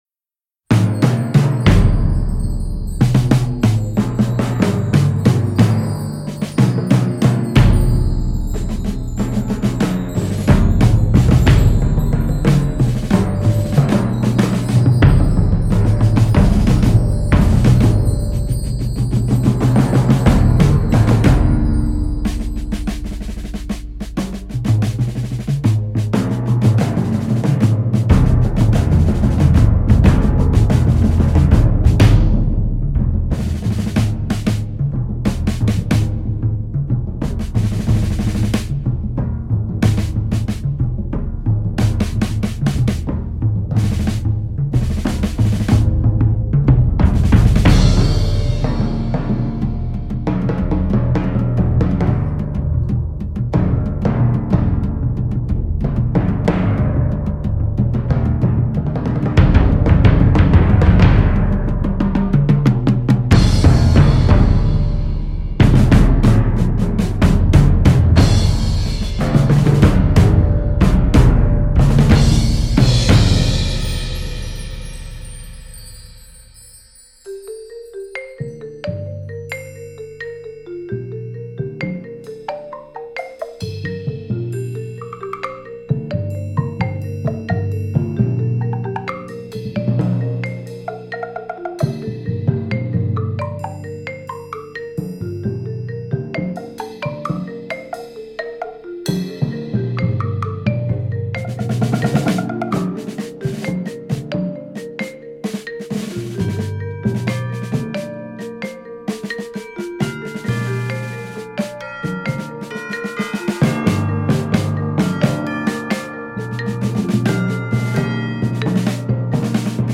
Voicing: Percussion Quartet